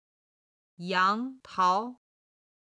軽声の音は音源の都合上、四声にて編集しています。
杨桃　(yáng táo)　スターフルーツ
17-yang2tao2.mp3